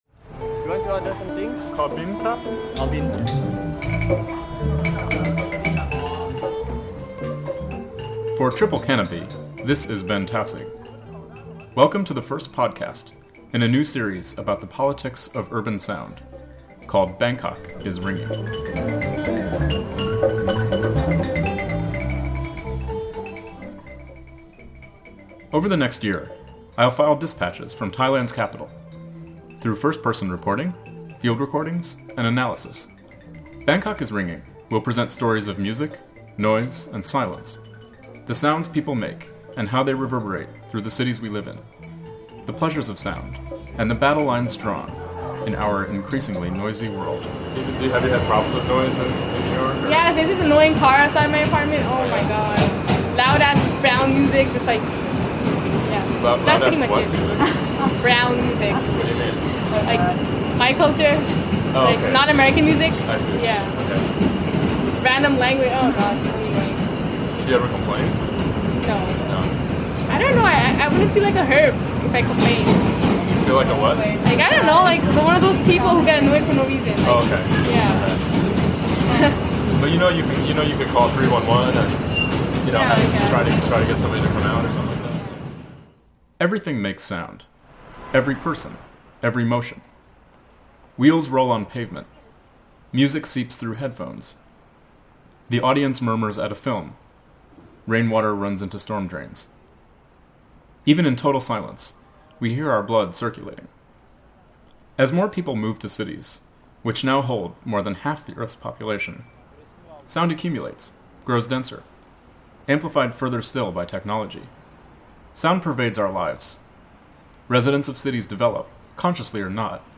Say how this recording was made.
Published on March 16, 2010 Download -:-- / -:-- A series exploring the politics of urban sound in Bangkok and beyond, through first-person reporting, field recordings, and analysis.